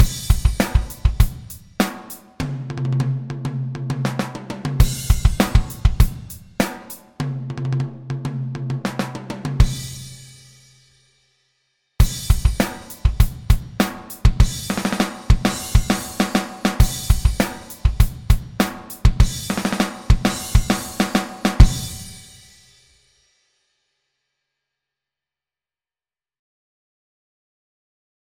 Sound Samples: Fill-Ins and Grooves